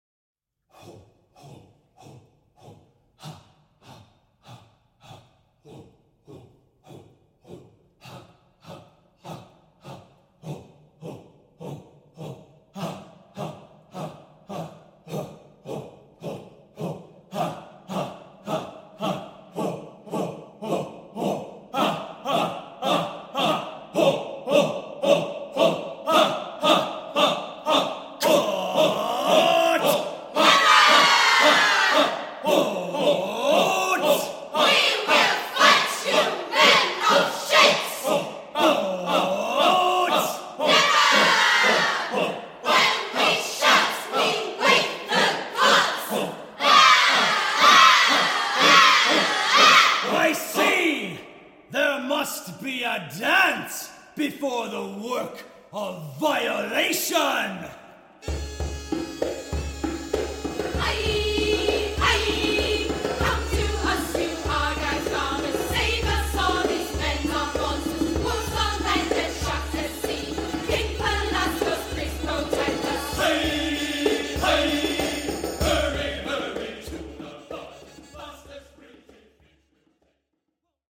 These are sample recordings from the 2016 Production of the play done at the Lyceum Theater in Edinburgh, Scotland.